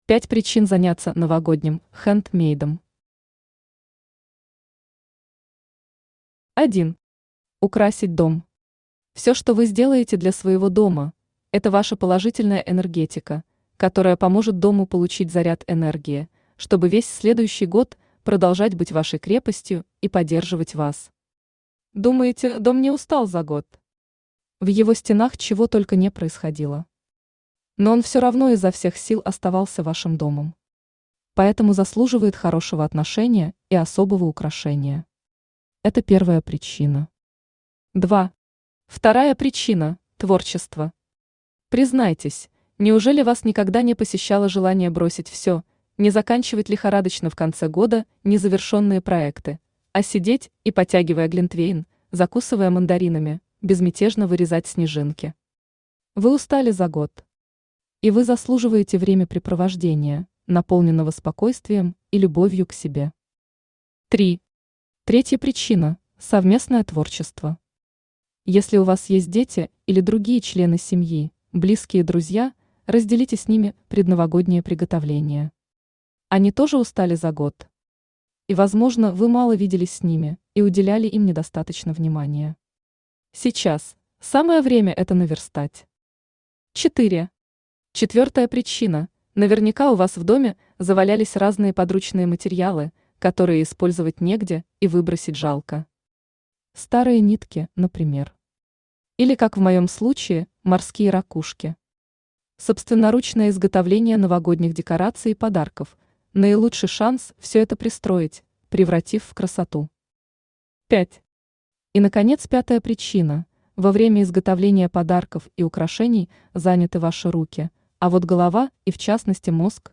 Аудиокнига Волшебные подарки своими руками. 5 красивых и легких новогодних мастер-классов + бонус | Библиотека аудиокниг
Читает аудиокнигу Авточтец ЛитРес.